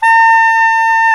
SAX SOPMFA0Q.wav